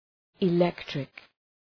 Προφορά
{ı’lektrık}